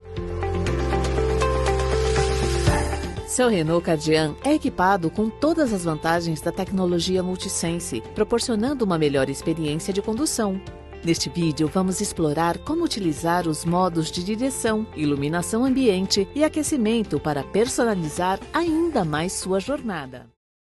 Explainer Videos
My voice is sweet, kind, friendly and conveys confidence and assurance.
Contralto
ConversationalFriendlyConfidentSincerePositive